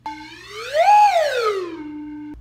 embolo_sube_e_baixa.mp3